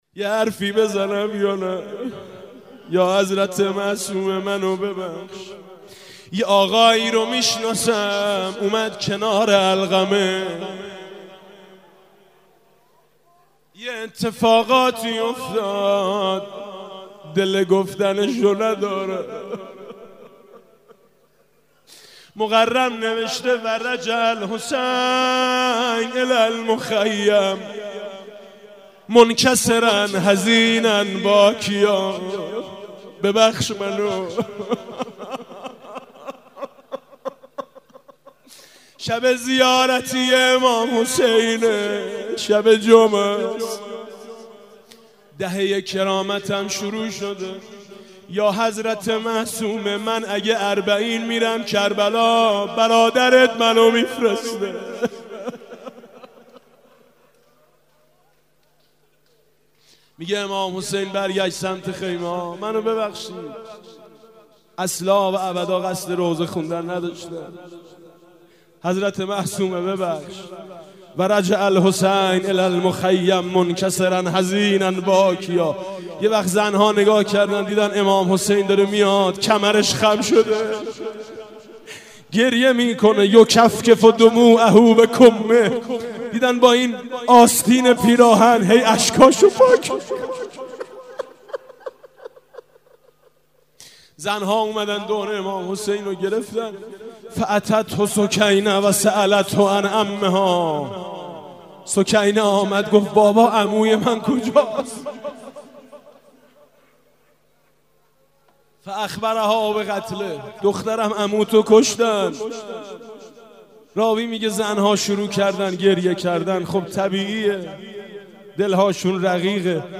ولادت حضرت معصومه (س)